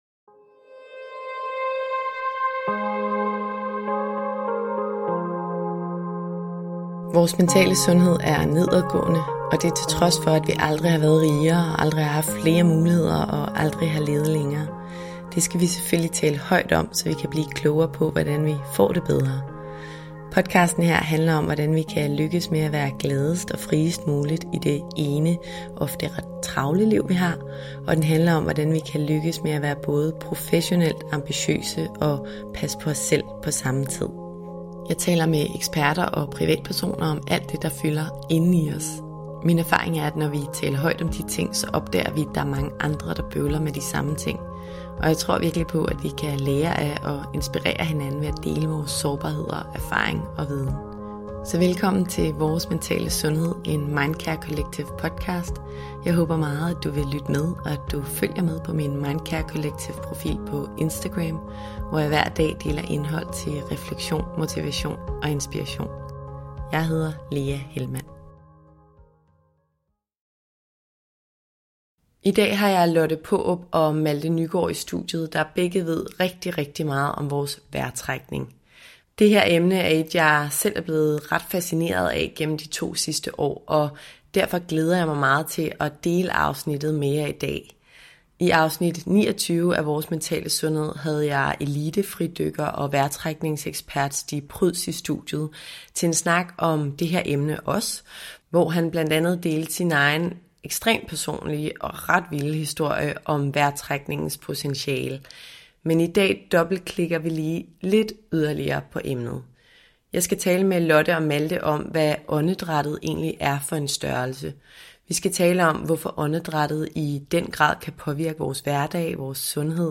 I denne uges afsnit har jeg to vejrtrækningseksperter